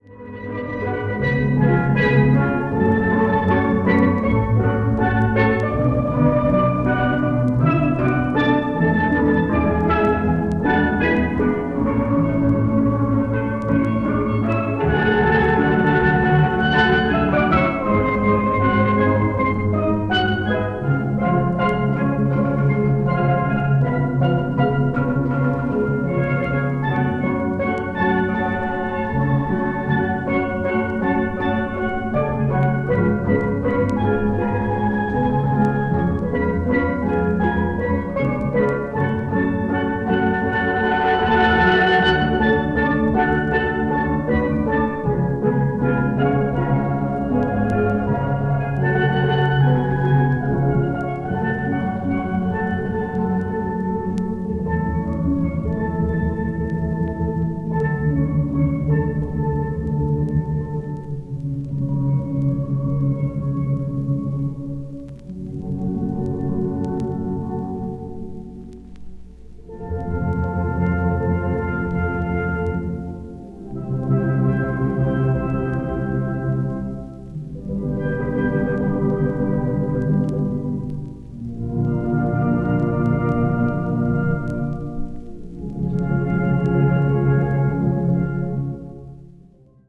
media : EX-/VG+(わずかなチリノイズ/一部軽いチリノイズ/軽いプチノイズが入る箇所あり)
carib   steel pan   trinidad tobago   tropical   world music